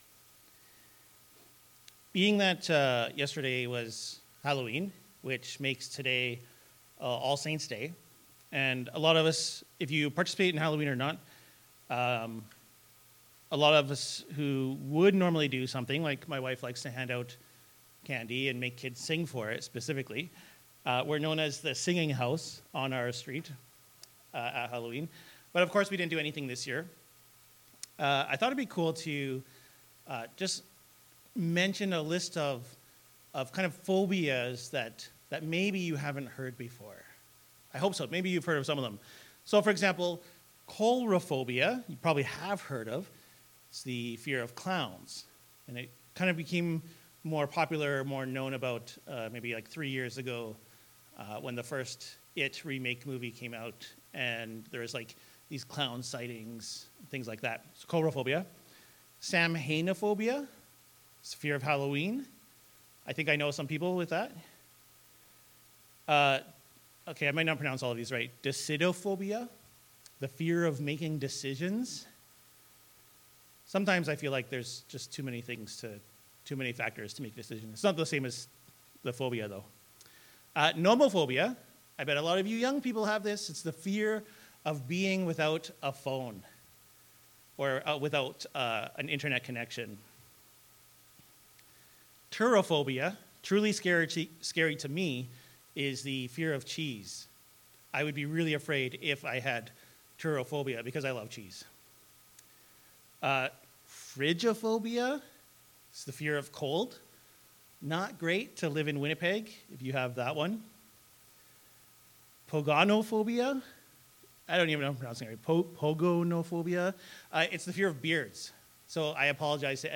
Note: Sermon audio ends at 23:50 mark. Service video begins at the 22:50 mark.